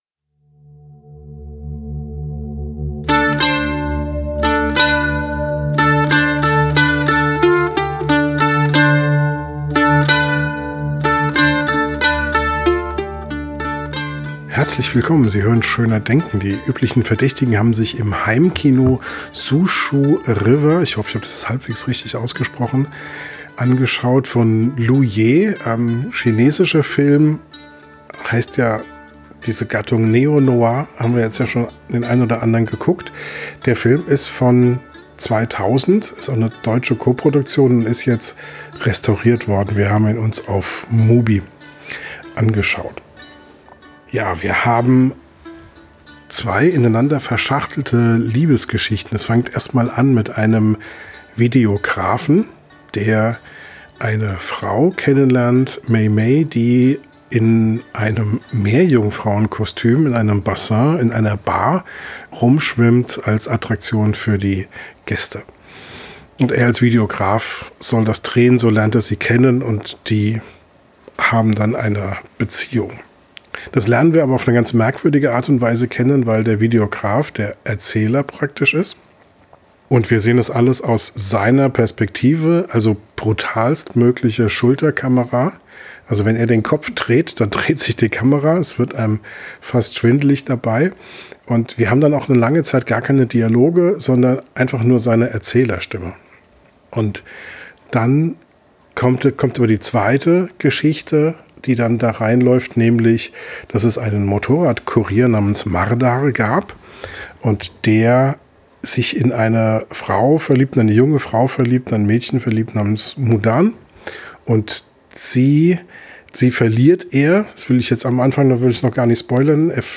Im Podcast direkt nach dem Film vergleichen wir SUZHOU RIVER mit ALL WE IMAGINE AS LIGHT.